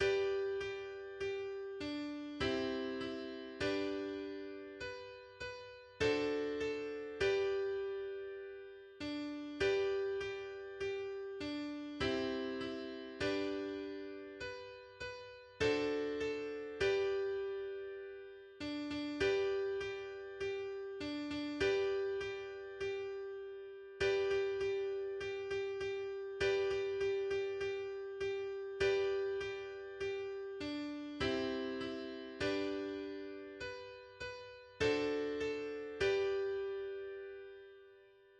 Folkvisa / barnramsa
GenreFolkvisa
Melodi till sången.